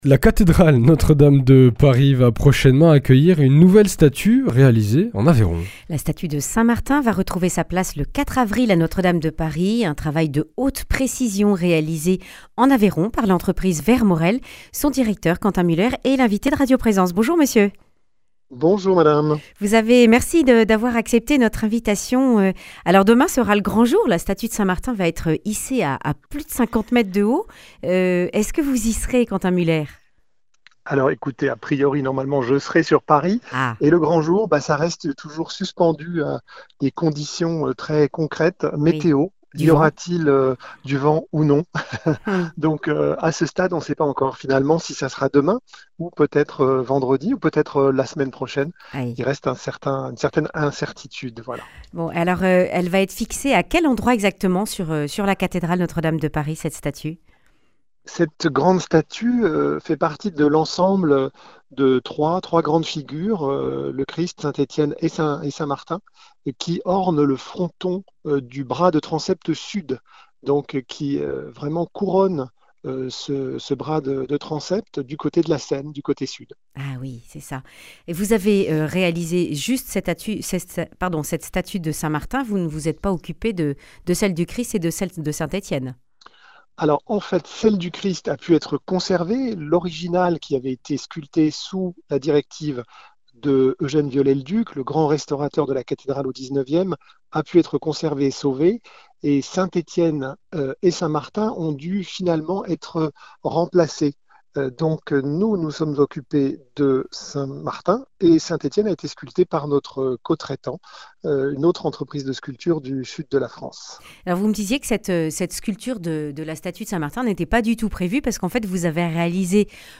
Accueil \ Emissions \ Information \ Régionale \ Le grand entretien \ Une nouvelle statue de saint Martin pour Notre Dame de Paris réalisée en (...)